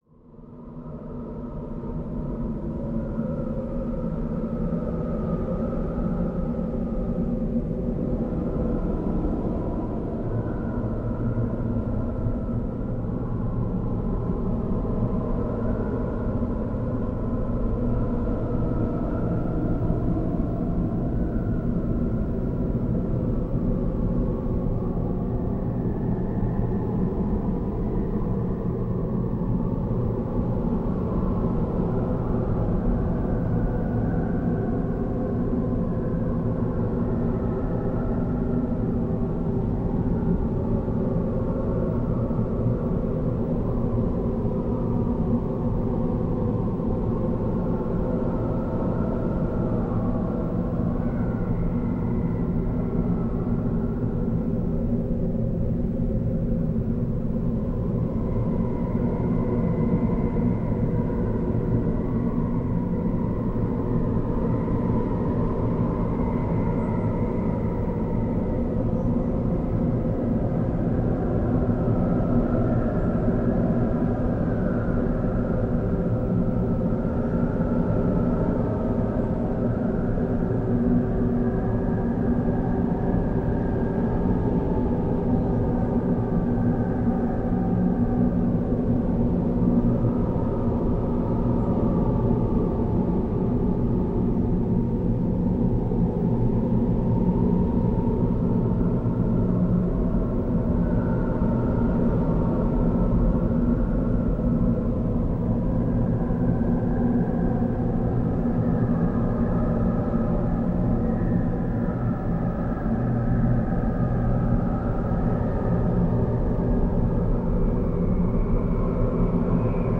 Здесь собраны записи, передающие мягкое дыхание туманного леса, шелест капель на паутине, приглушенные голоса природы.
Звук шагов в густом тумане, атмосферный шум